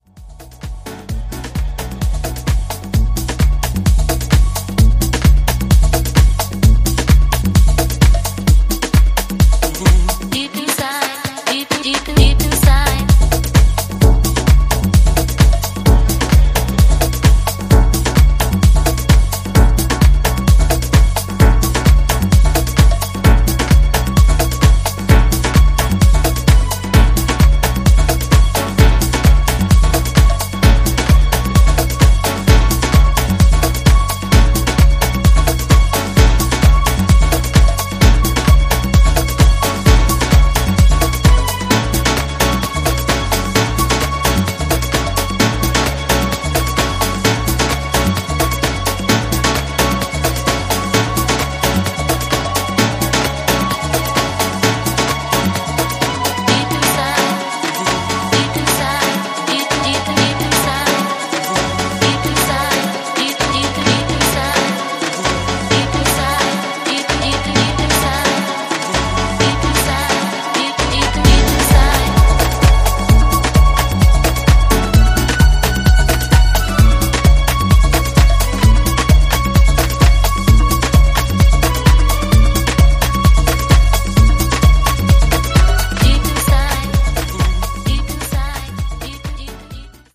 各曲フレッシュな勢いで溢れており、ピークタイムを気持ちよく沸かせてくれることでしょう！